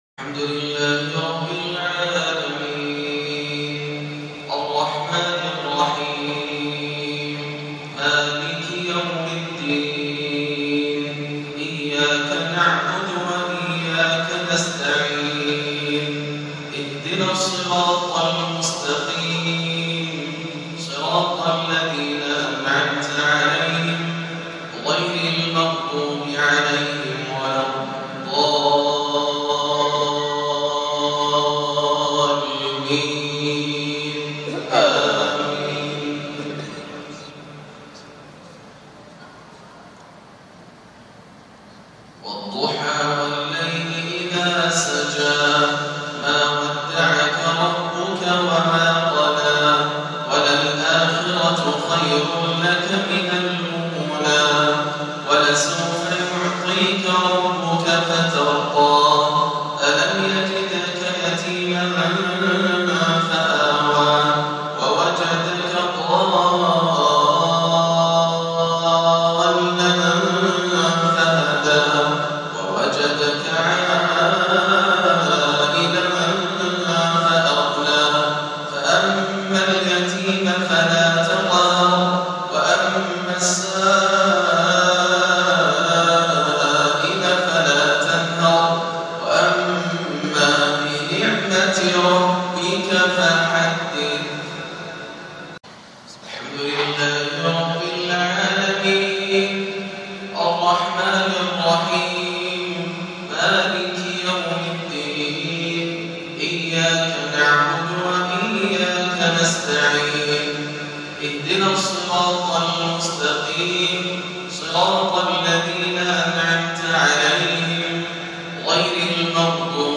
مغرب الأحد 9-2-1439هـ سورتي الضحى و الشرح > عام 1439 > الفروض - تلاوات ياسر الدوسري